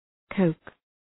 Προφορά
{kəʋk}
coke.mp3